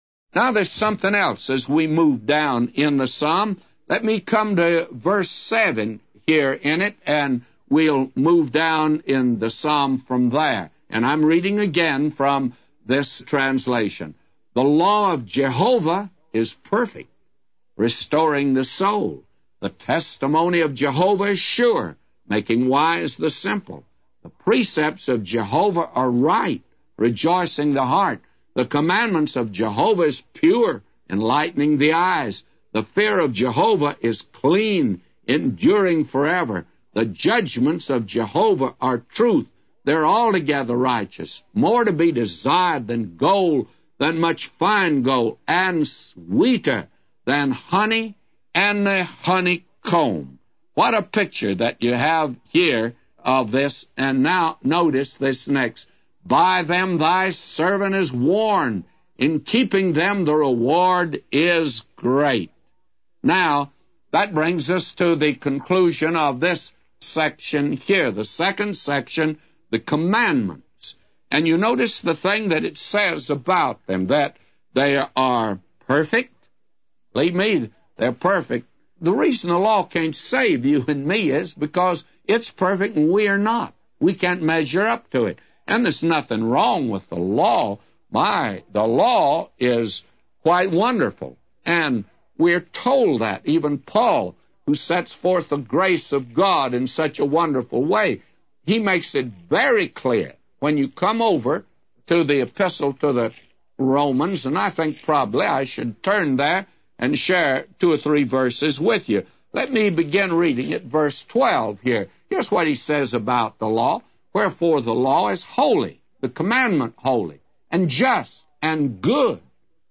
A Commentary By J Vernon MCgee For Psalms 19:7-999